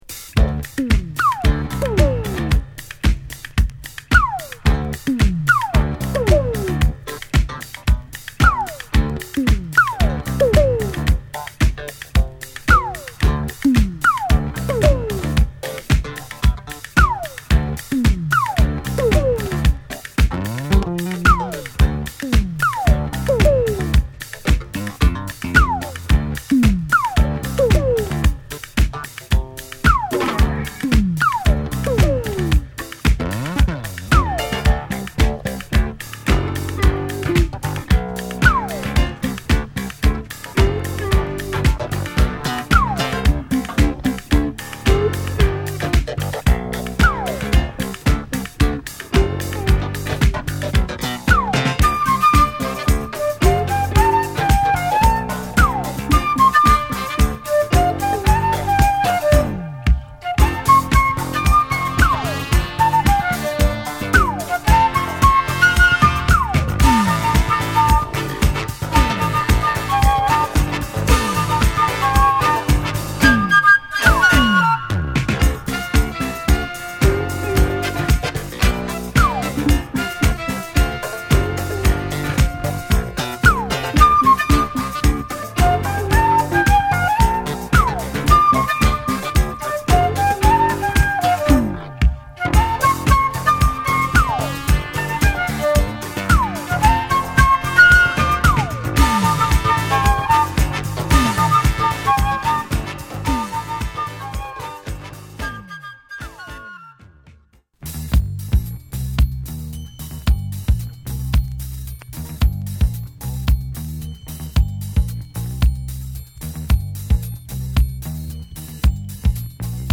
テキサス出身の女性フルート奏者